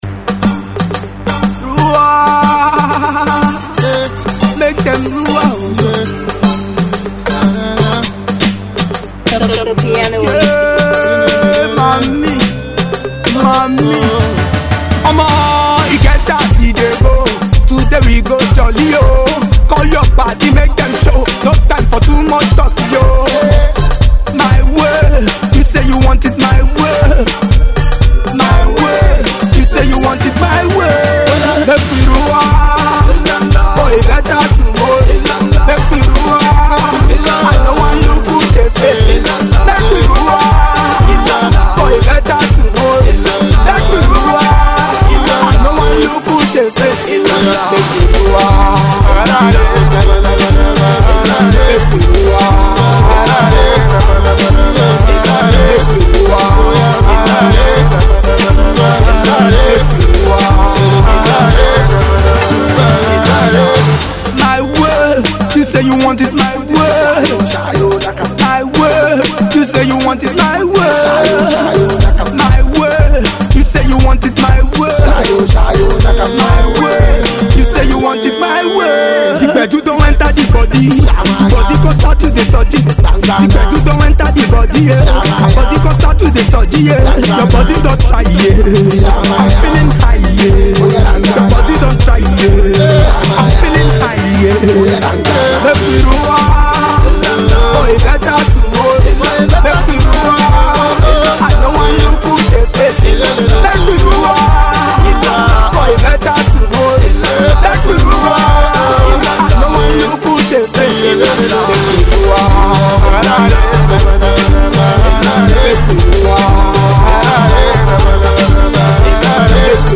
Pop 0 20 University of…